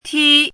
怎么读
剔 [tī]